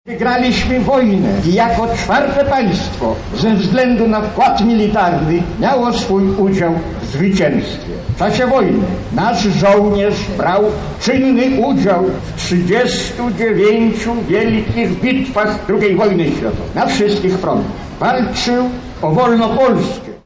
W Lublinie odbyły się uroczystości upamiętniające ofiary II wojny światowej.